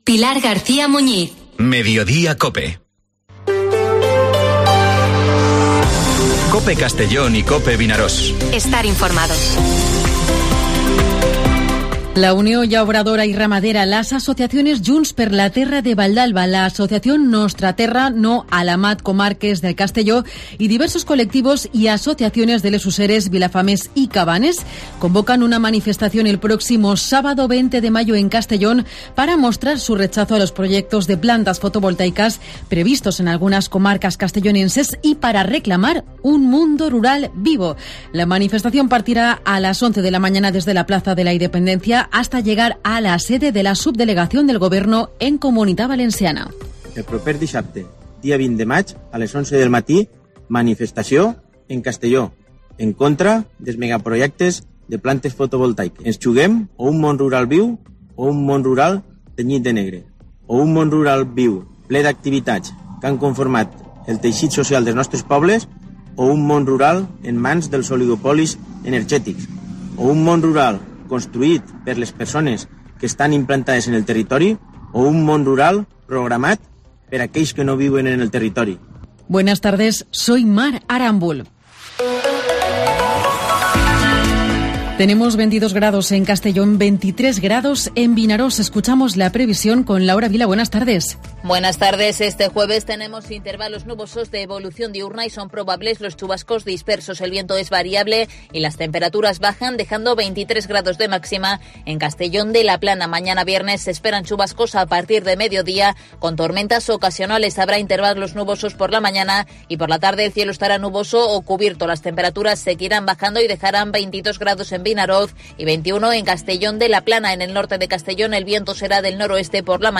Informativo Mediodía COPE en Castellón (11/05/2023)